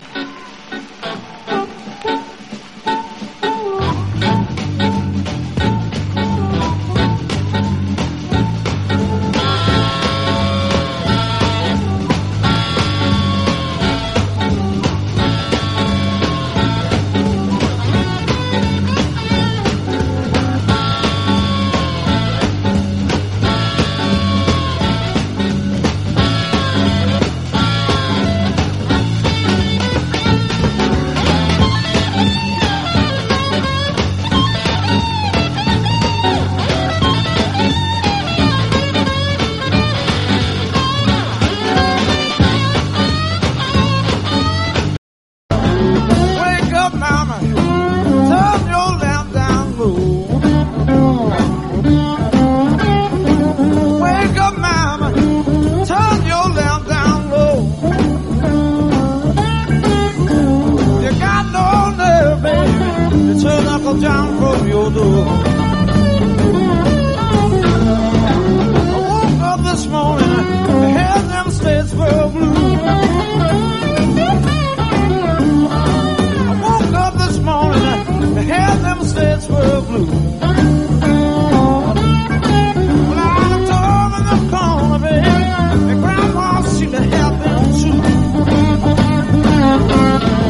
ROCK / 70'S
情熱的インスト最高峰